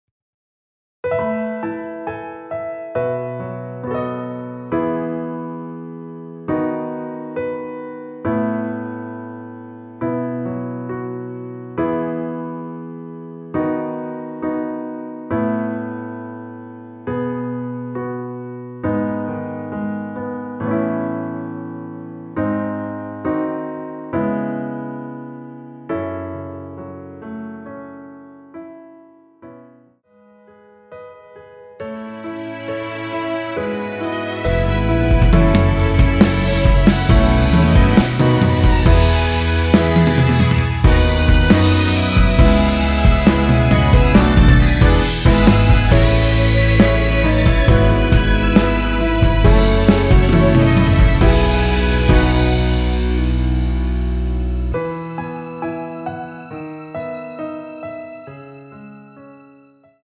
원키에서(+1)올린 MR입니다.
◈ 곡명 옆 (-1)은 반음 내림, (+1)은 반음 올림 입니다.
앞부분30초, 뒷부분30초씩 편집해서 올려 드리고 있습니다.